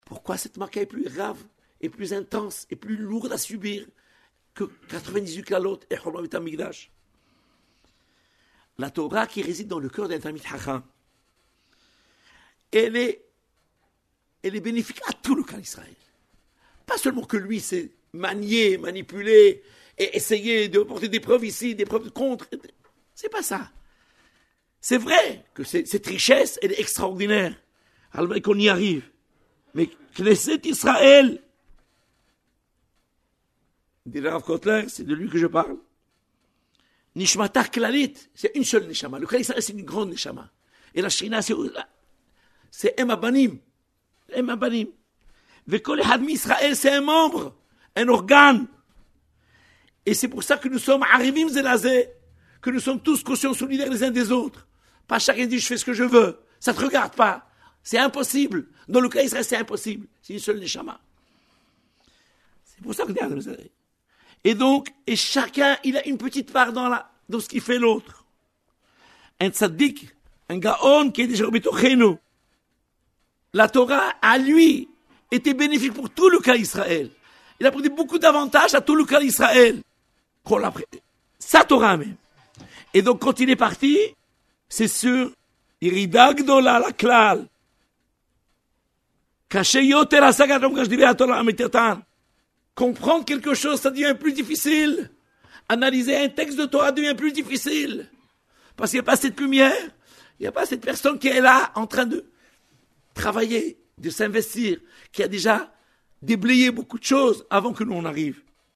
Nous entrons de plein pied dans l’atmosphère de la Shoule et du Beith Hamidrash MEKOR ‘HAÏM à Paris